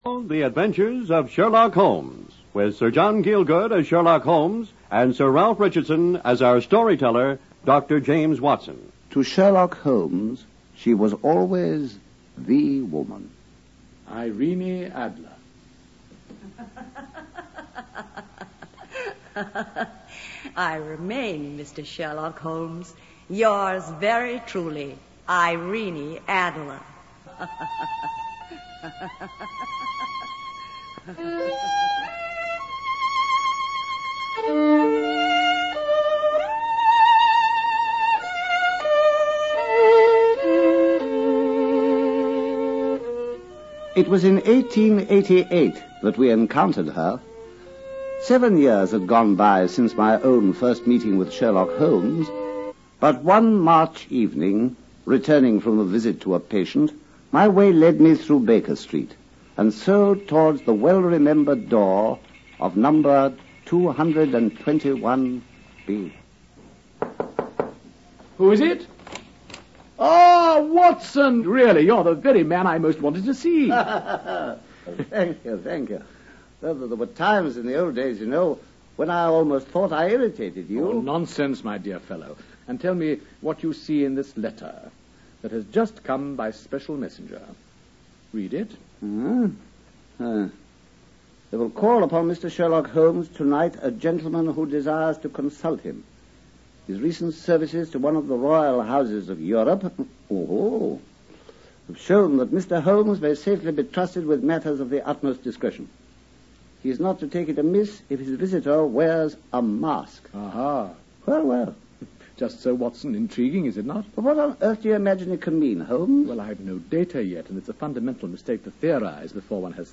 Sir John Gielgud (Sherlock Holmes) and Sir Ralph Richardson (Dr. Watson) star in this old-time radio show dramatization of Sir Arthur Conan Doyles Sherlock Holmes detective stories.